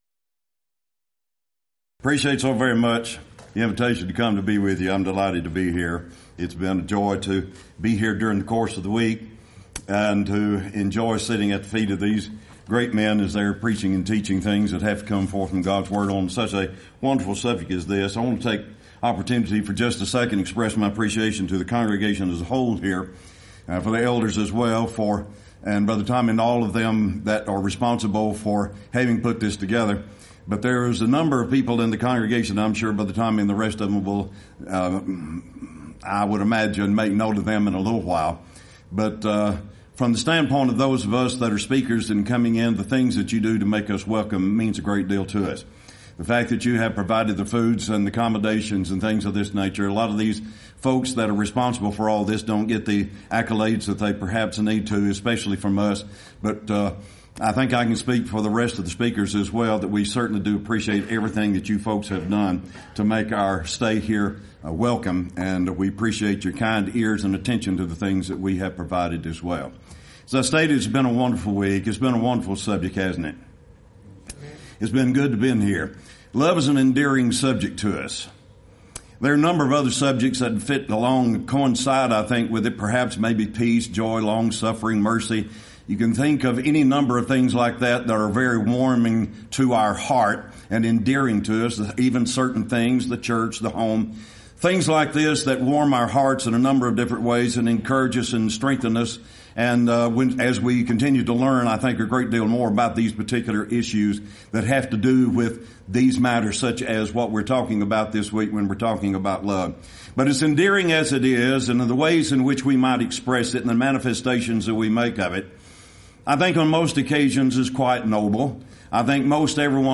Series: Lubbock Lectures Event: 26th Annual Lubbock Lectures
If you would like to order audio or video copies of this lecture, please contact our office and reference asset: 2024Lubbock26